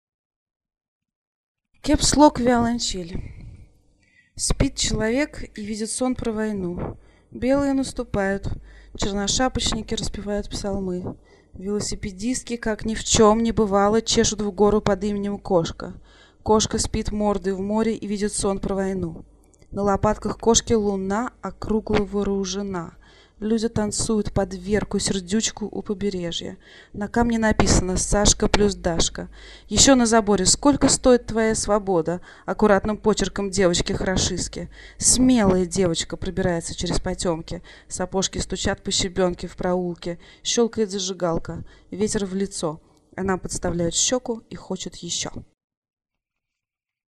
поэзия
CapsLock виолончель